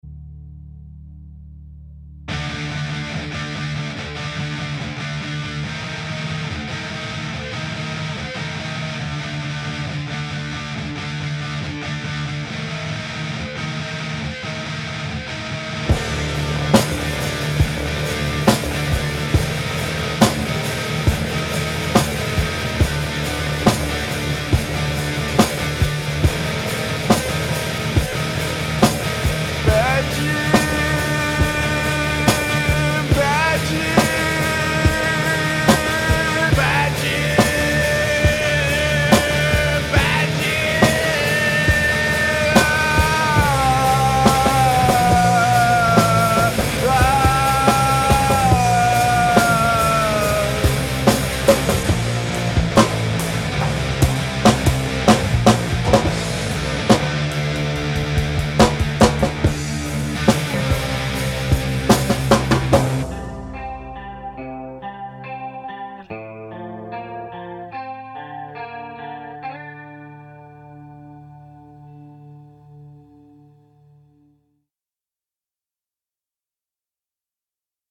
I'm sending a new version with less compression overall and almost no reverb using parallel compression on kick and snare and the drums bus. The snare is sort of all over the place now, but its closer to the raw version.
Thats way nicer and natural..
I now hear dynamics in the guitar and the drums.